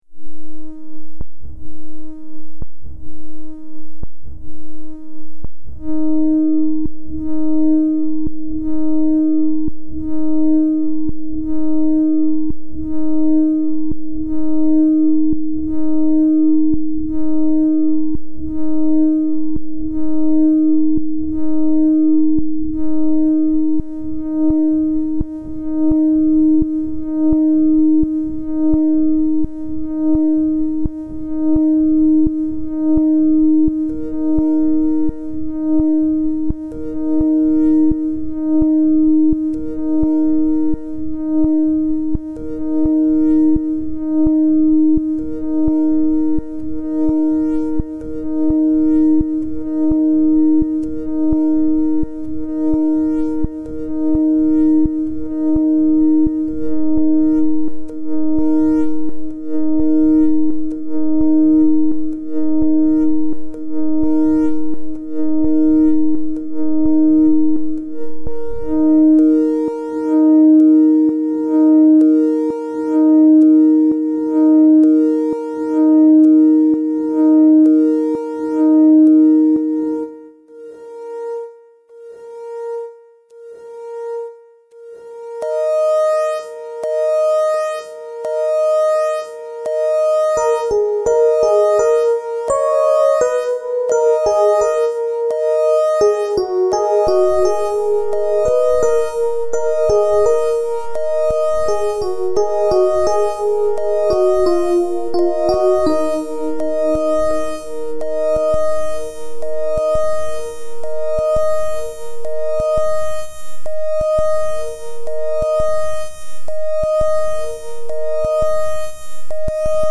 пять каверов